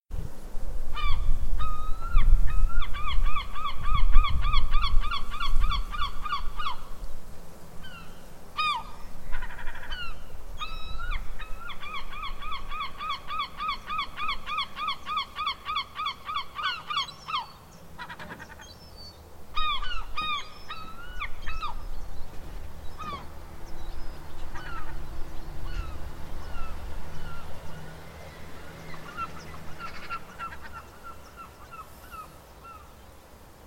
دانلود صدای مرغان دریایی از ساعد نیوز با لینک مستقیم و کیفیت بالا
جلوه های صوتی
برچسب: دانلود آهنگ های افکت صوتی انسان و موجودات زنده